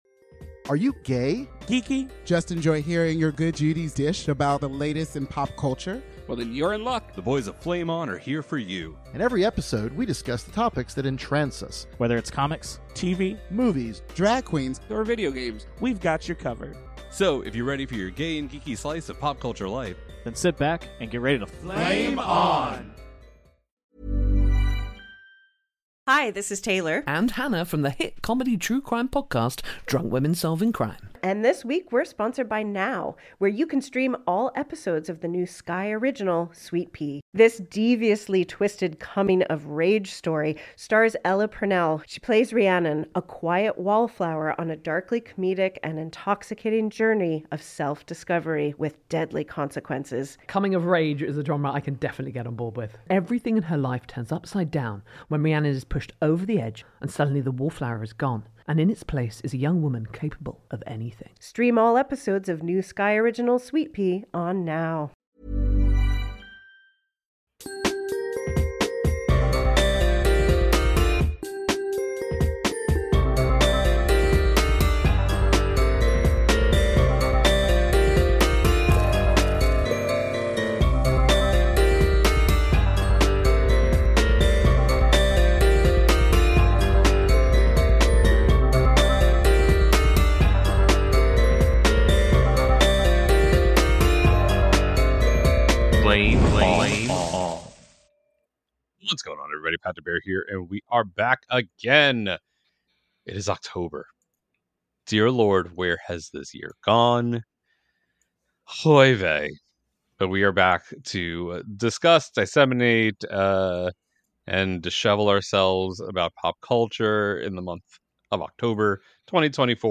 In this round table discussion